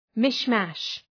{‘mıʃmæʃ}